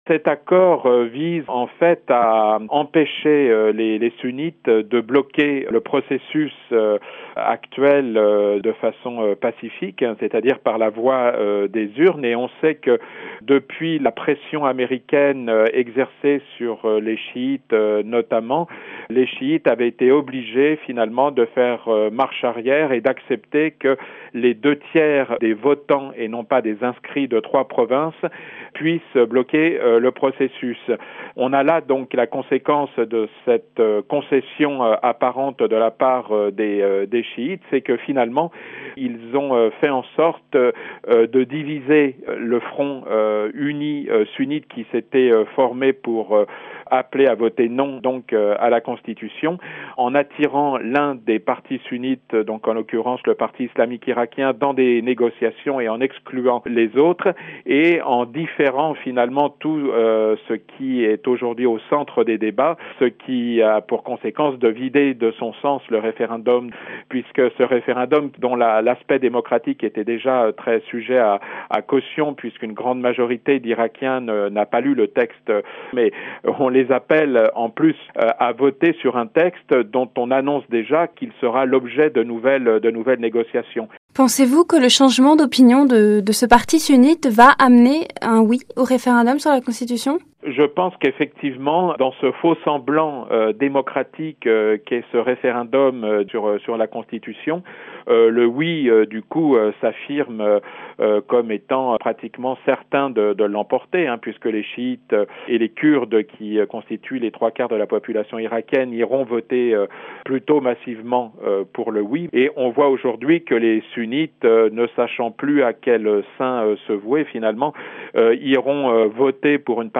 interrogé par